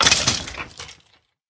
sounds / mob / skeleton / death.ogg
death.ogg